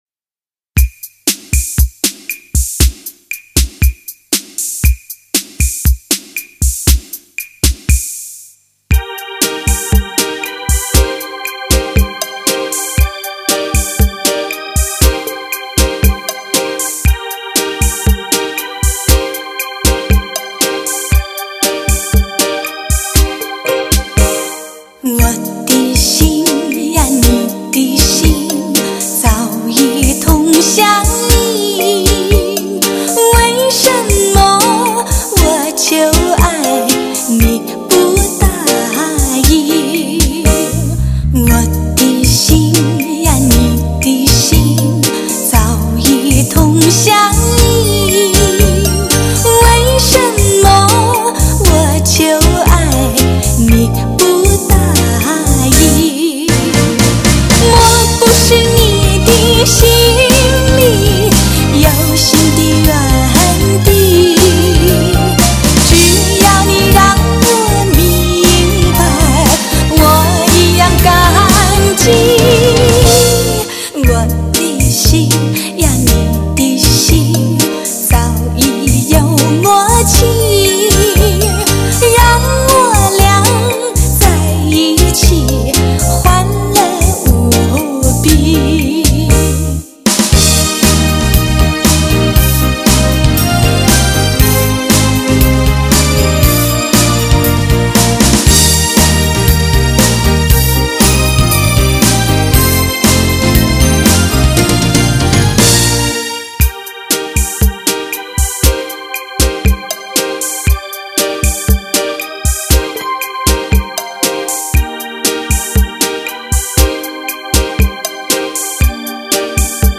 DTS-ES6.1